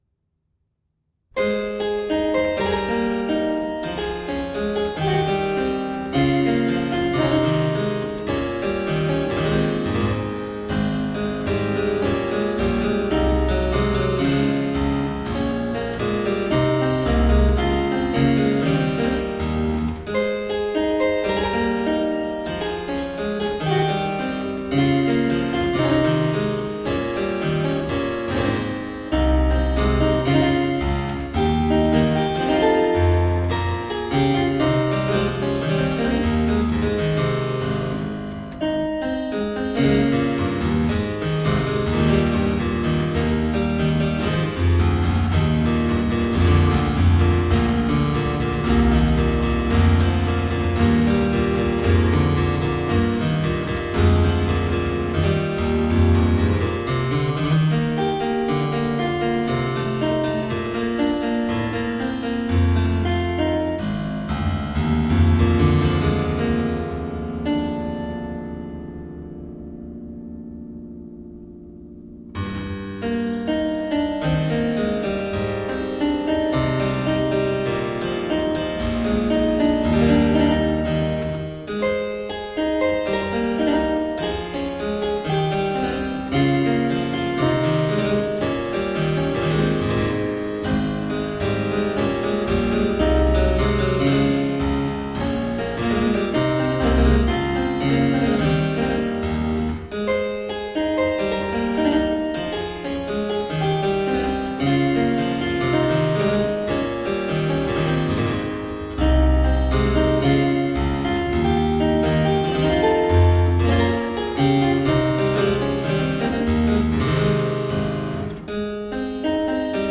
Vibrant harpsichord-music.
Classical, Baroque, Instrumental
Harpsichord